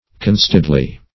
Conceitedly \Con*ceit"ed*ly\, adv.